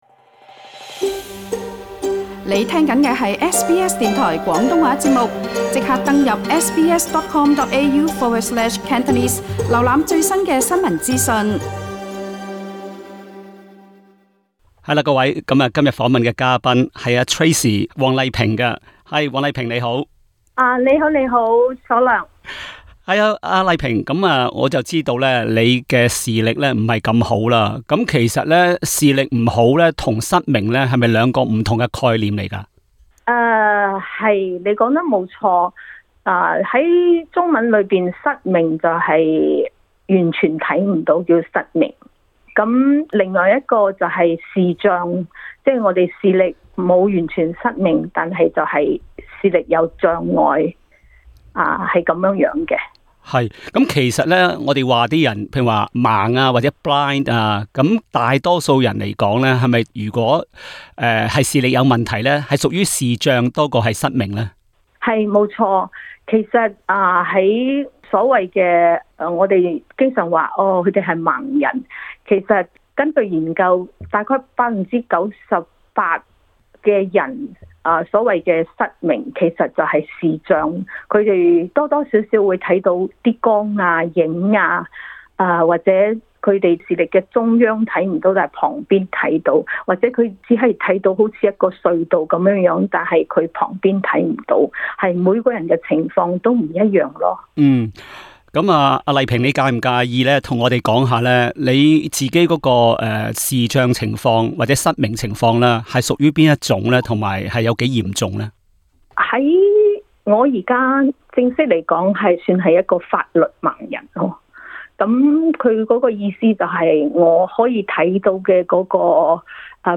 SBS广东话播客